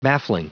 Prononciation du mot baffling en anglais (fichier audio)
Prononciation du mot : baffling